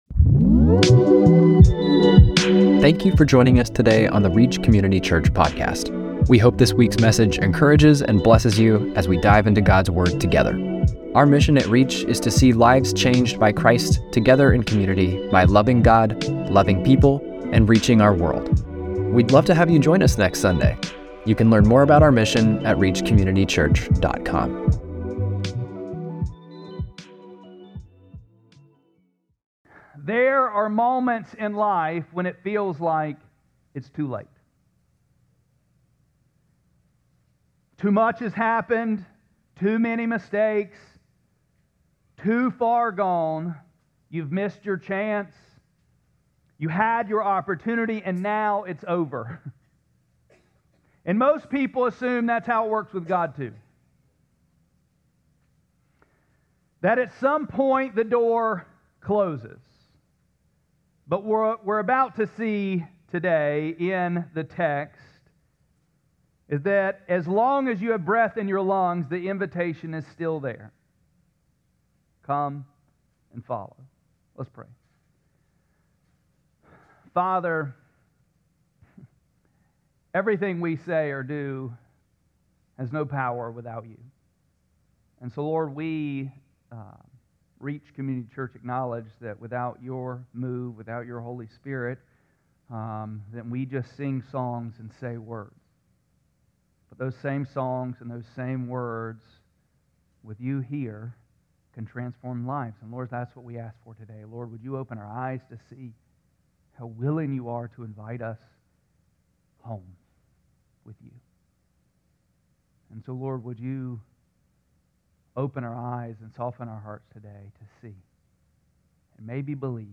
4-5-26-Sermon.mp3